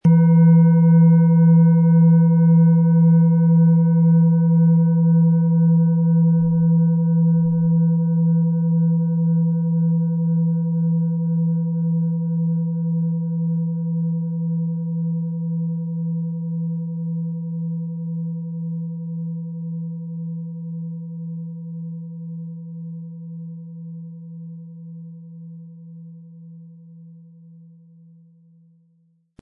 Hopi Herzton
• Tiefster Ton: Mond
PlanetentöneHopi Herzton & Mond
MaterialBronze